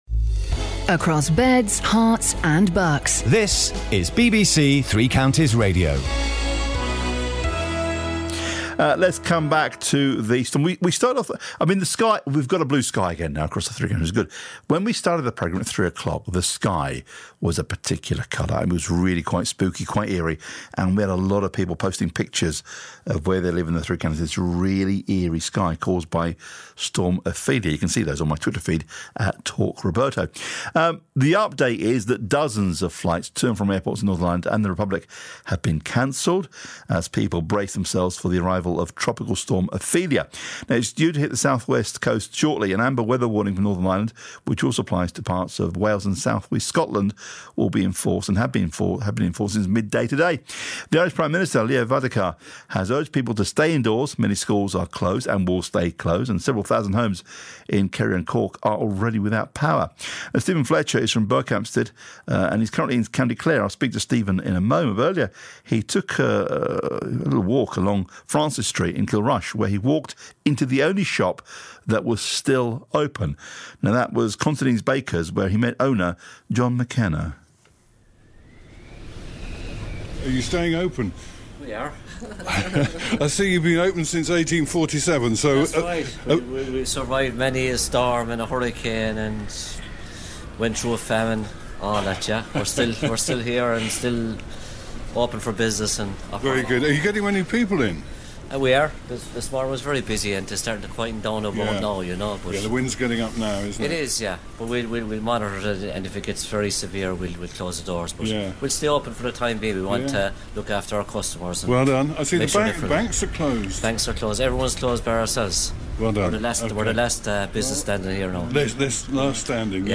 3CR-Interview-16OCT17.mp3